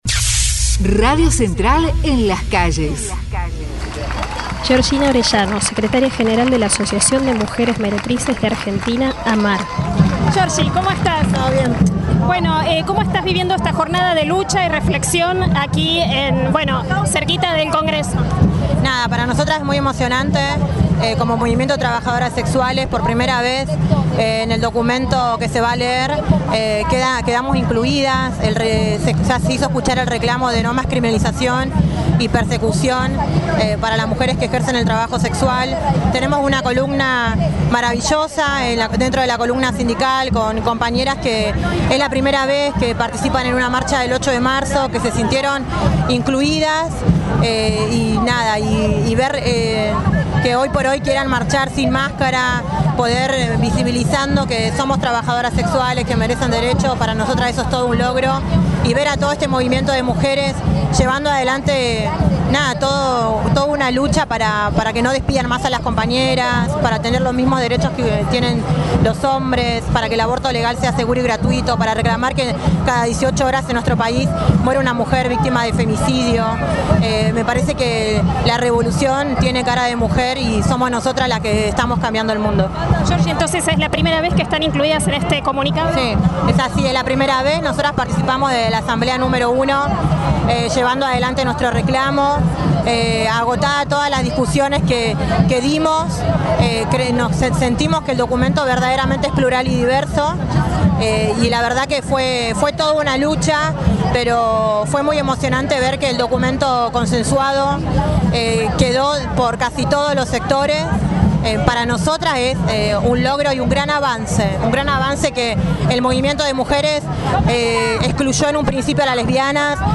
PARO INTERNACIONAL DE LAS TRABAJADORAS // Mujeres referentes de la CTA 2